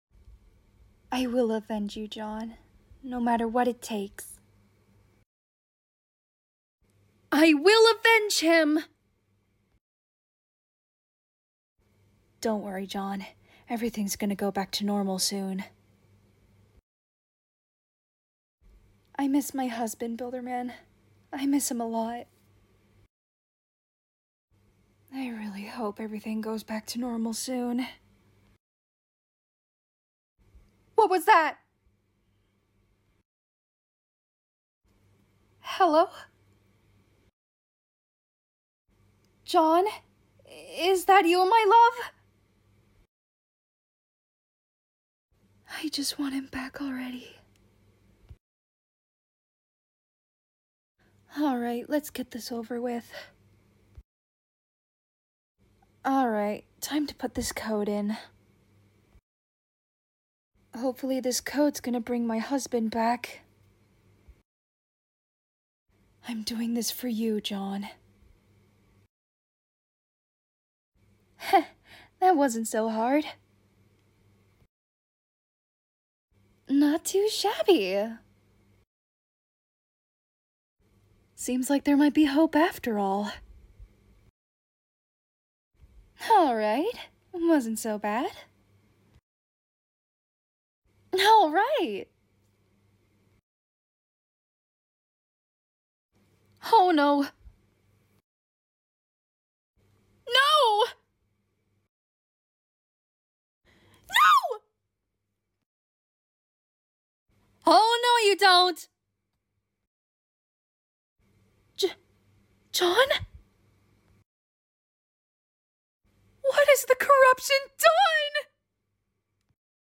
Rules Of Robloxia: The Last Term voice lines (Part: Jane Doe)